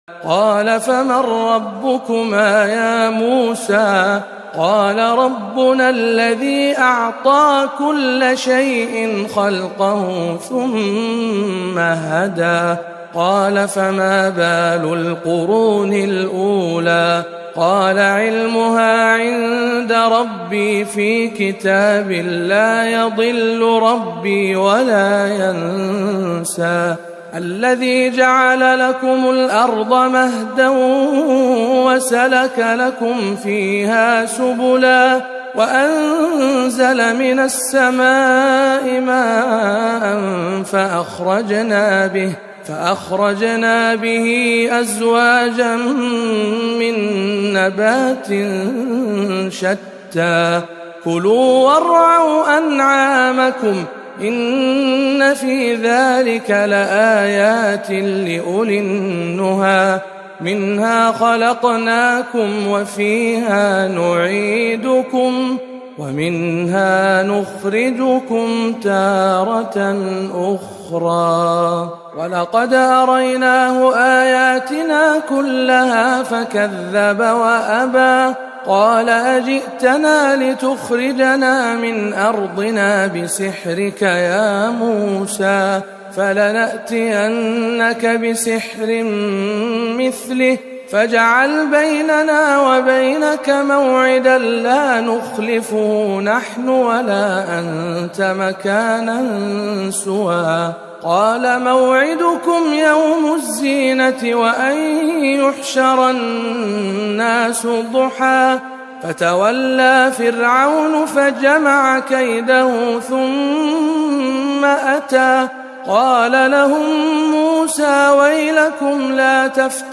تلاوة مميزة من سورة طه